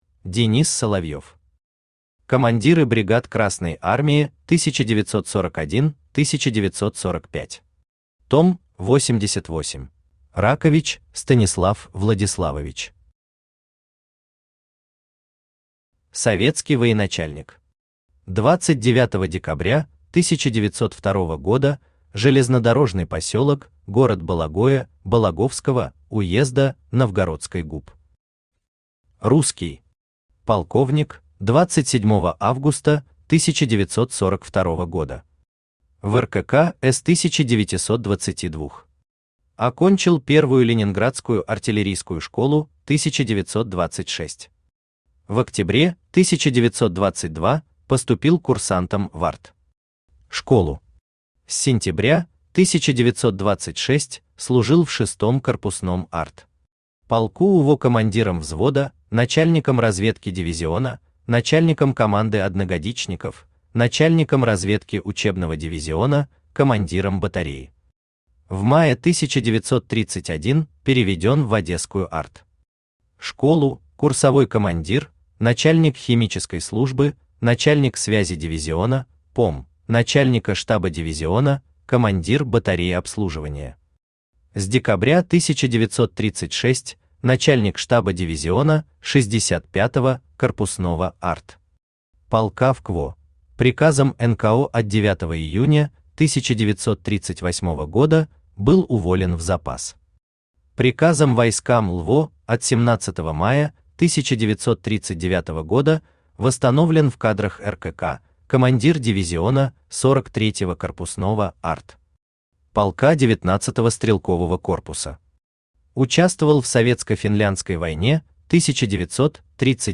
Аудиокнига Командиры бригад Красной Армии 1941-1945. Том 88 | Библиотека аудиокниг
Том 88 Автор Денис Соловьев Читает аудиокнигу Авточтец ЛитРес.